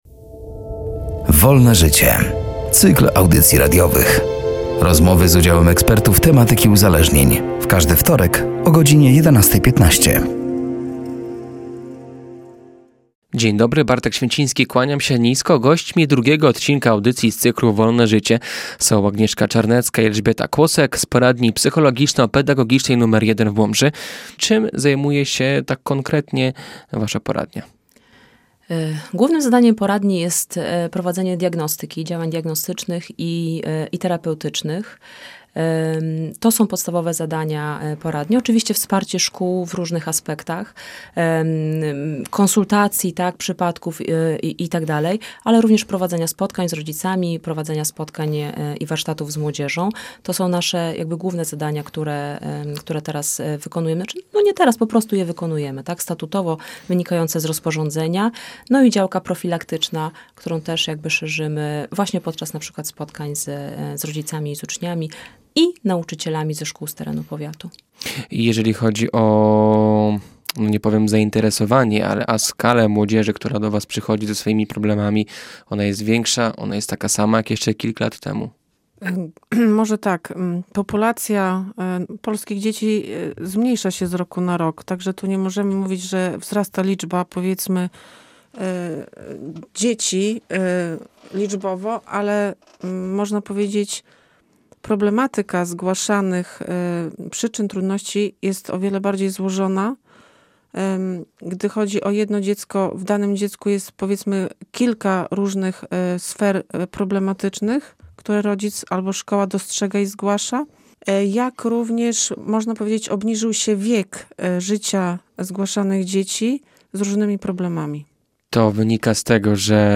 „Wolne życie” to cykl audycji radiowych. Rozmowy z udziałem ekspertów z obszaru psychologii i uzależnień.